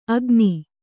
♪ agni